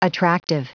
Prononciation du mot attractive en anglais (fichier audio)
Prononciation du mot : attractive